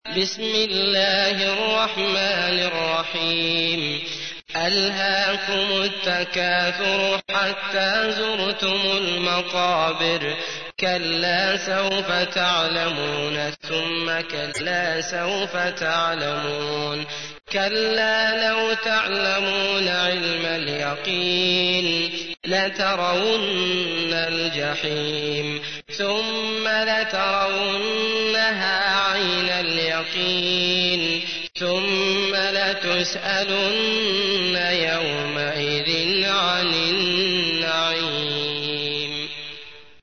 تحميل : 102. سورة التكاثر / القارئ عبد الله المطرود / القرآن الكريم / موقع يا حسين